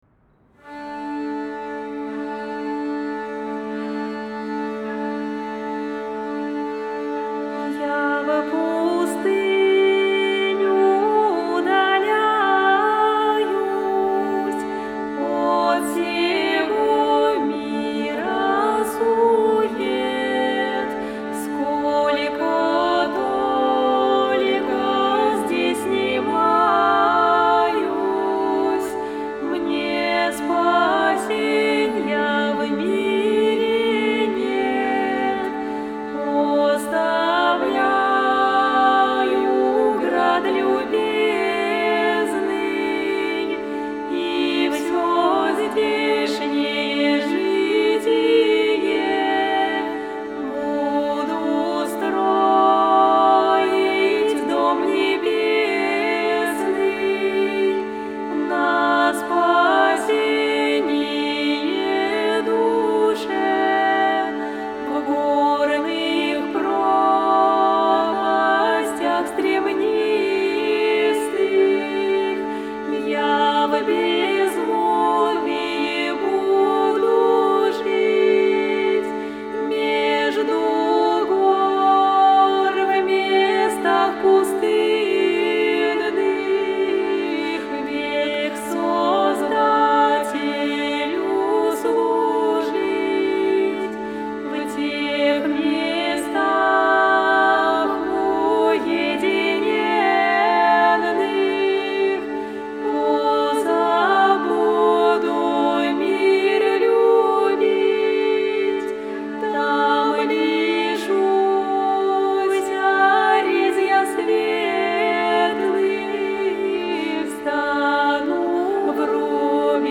Я исполняю оригинальный голос
Стих
«Я в пустыню удаляюсь». 19 век, письменная традиция